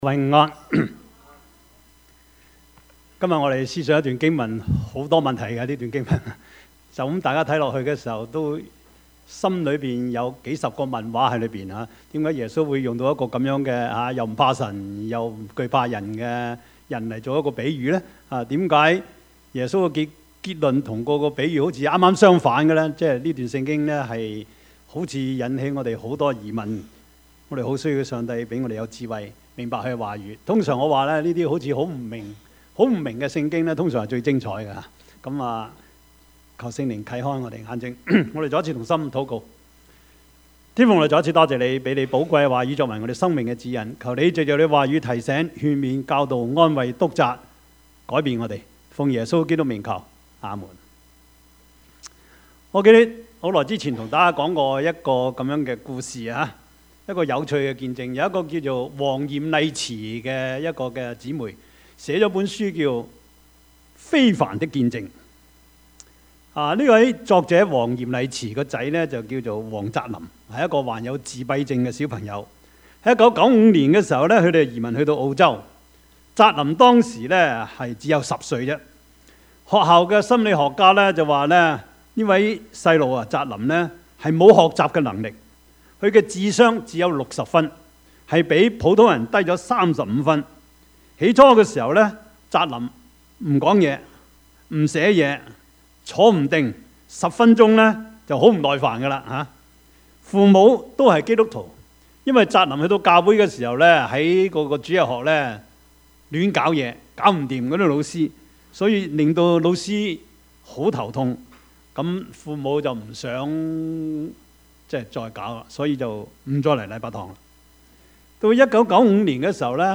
Service Type: 主日崇拜
Topics: 主日證道 « 眾人都找你 市場､試場 »